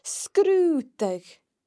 The broad sgr sound can also be heard in sgrùdadh (an investigation):